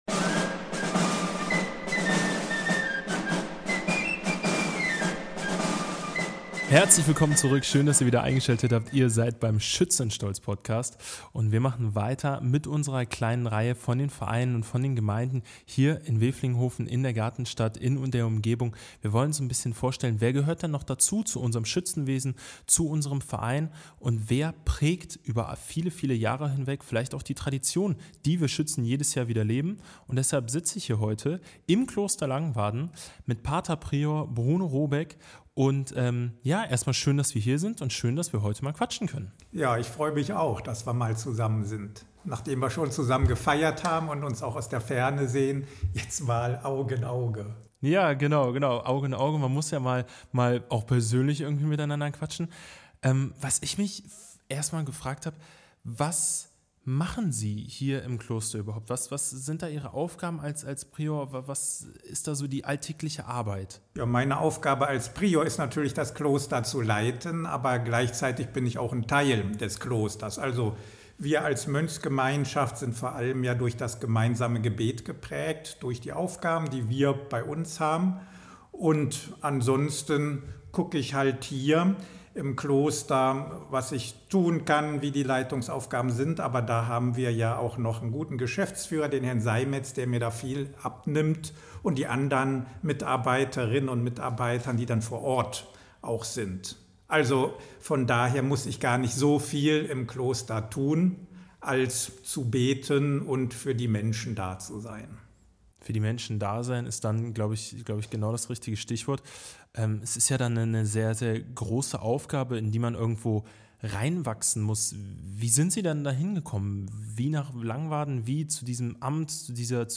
Kloster, Kirche, Weihnachten – ein Gespräch aus Langwaden (#44) ~ Schützenstolz Podcast